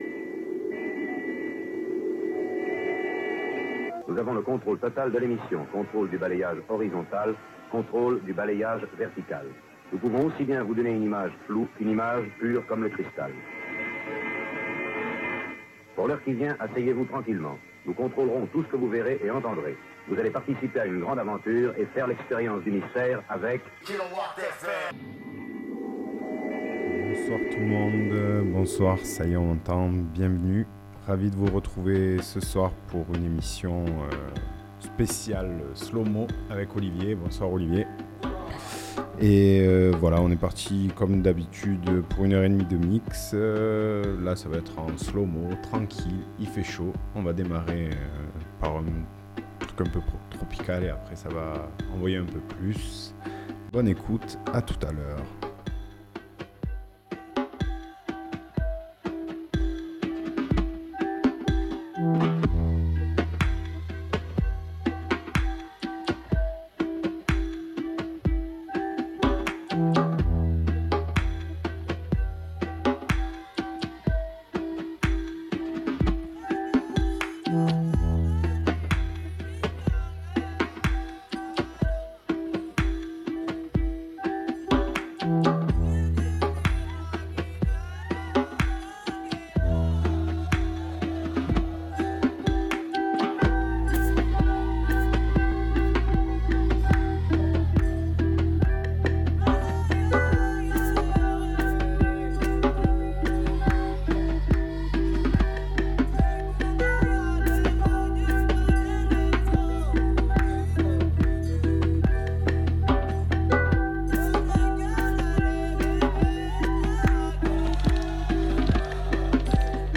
Il nous propose un rendez-vous hebdomadaire le mardi de 20h30 à 22h pour partager avec les auditeurs de Fréquence Mistral Digne des musiques variées allant du Rhythm and Blues, au Hip-Hop.